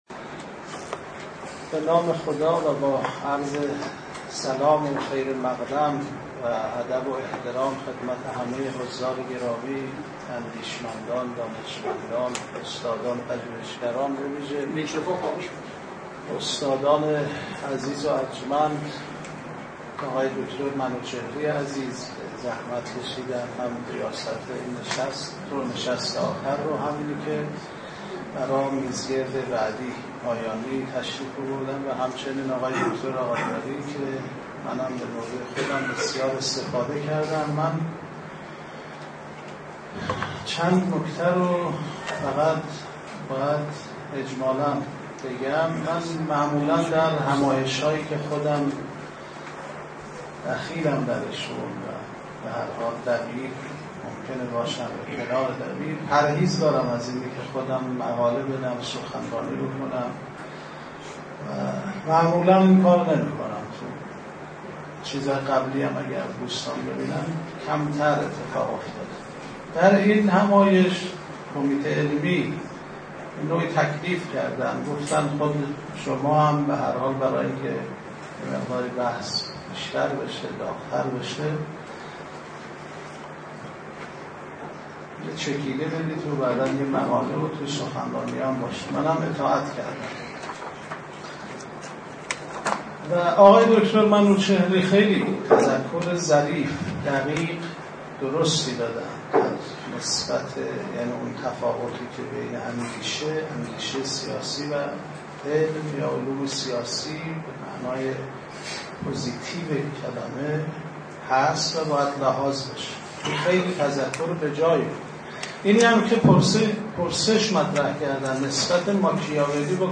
سخنرانی
در همایش تاریخ و علوم سیاسی